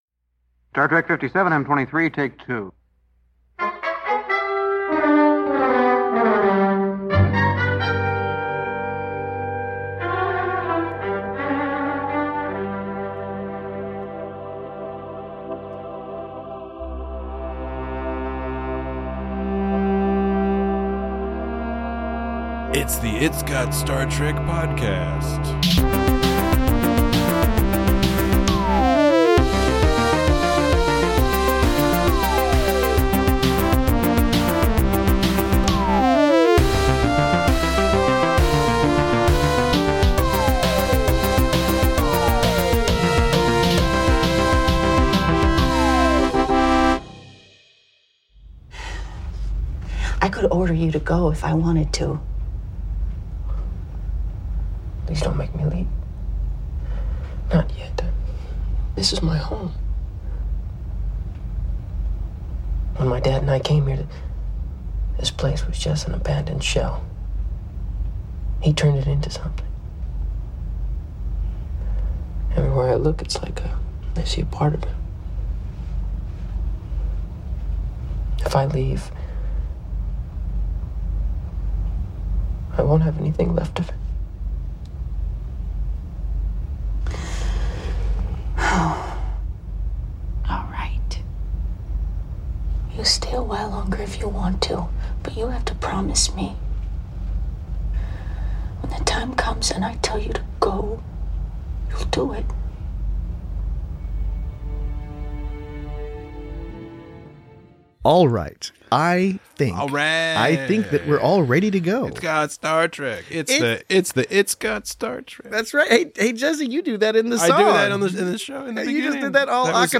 Join your teary-eyed hosts as they discuss love, loss, life, and all manner of other serious things while somehow managing to retain the expected light-hearted tone of the It's Got Star Trek program.